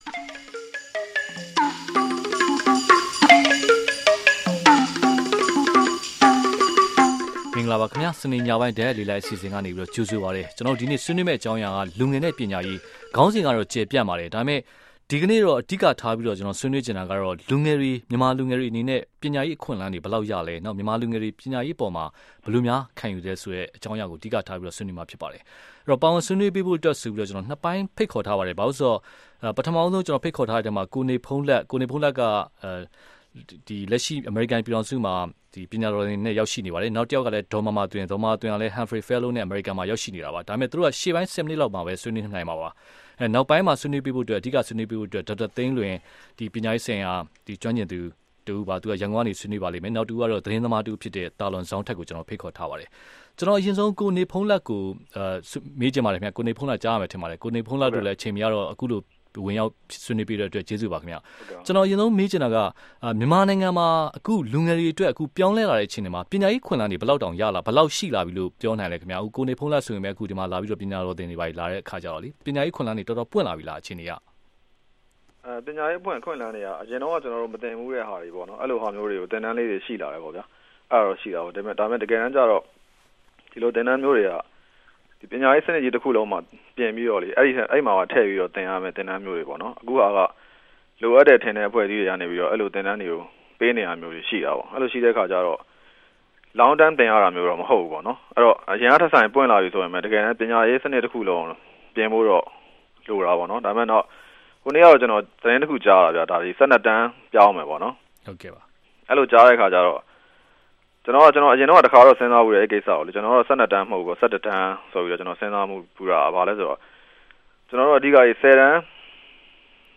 CALL IN SHOW - EDUCATION